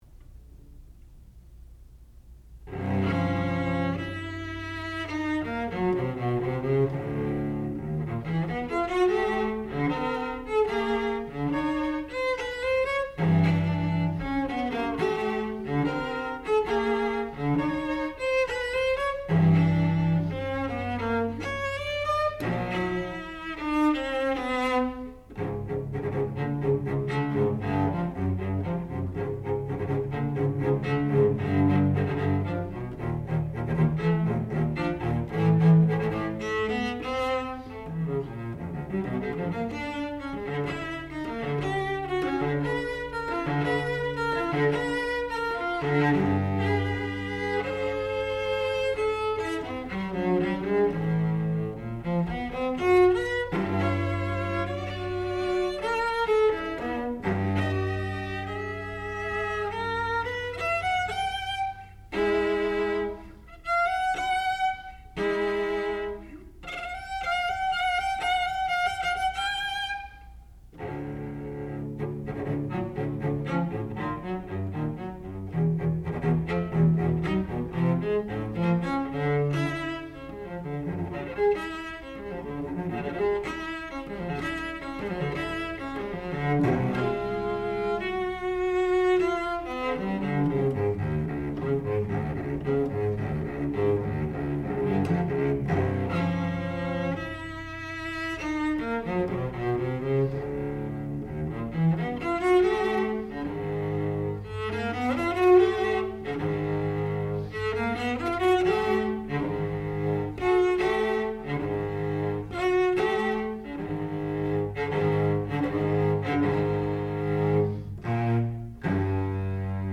Sonata, op. 25, no. 3 for violoncello solo
classical music
Advanced recital